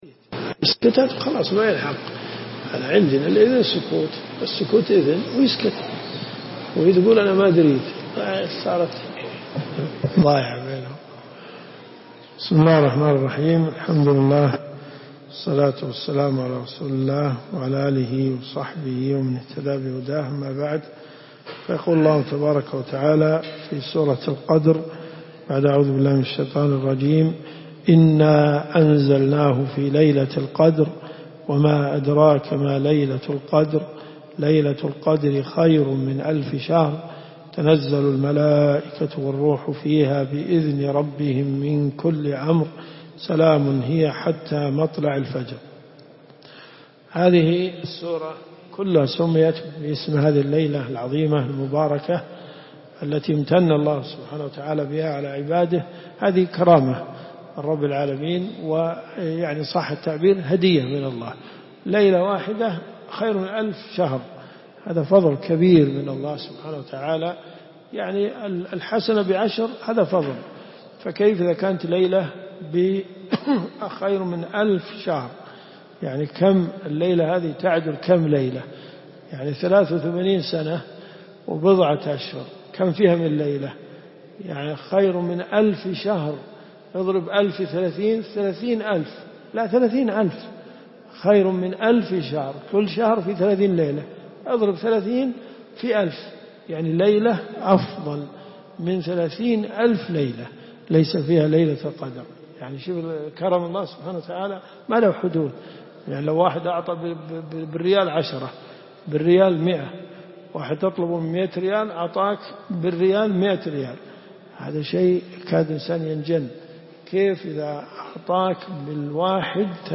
تفسير القران الكريم
دروس صوتيه ومرئية تقام في جامع الحمدان بالرياض